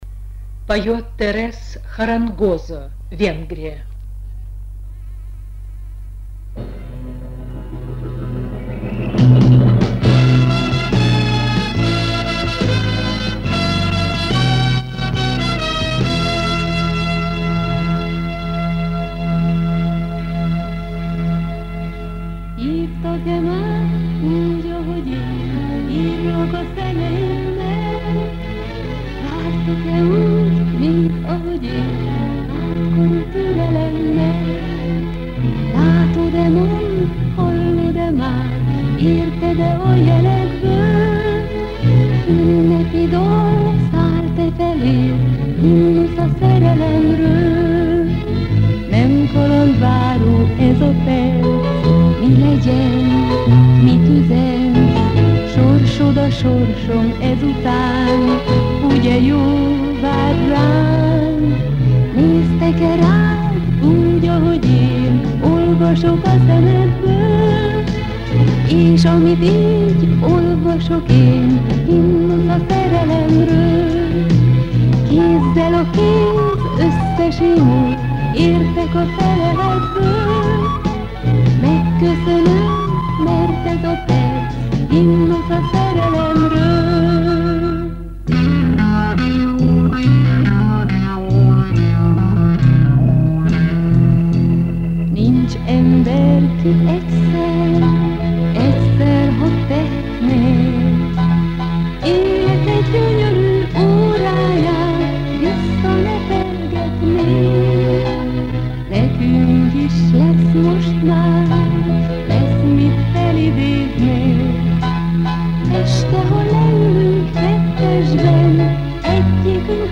Это оцифровка бобины с записями 1972 -1975 годов.